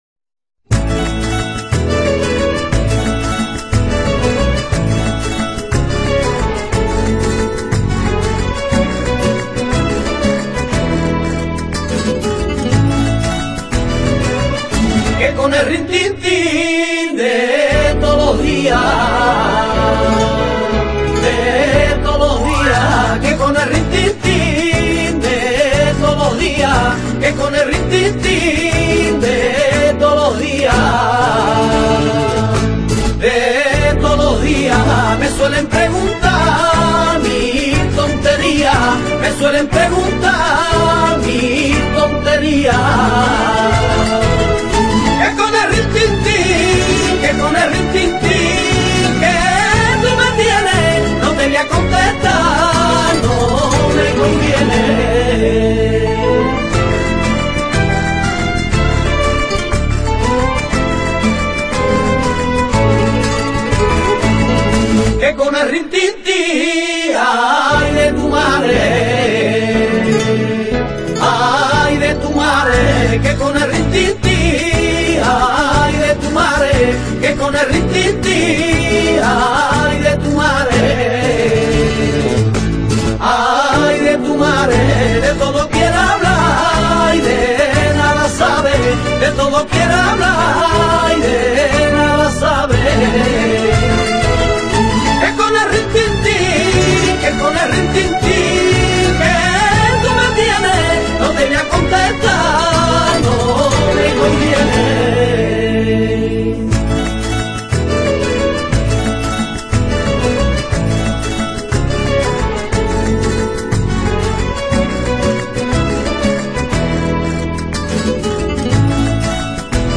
Una de las sevillanas más pegadizas de los últimos años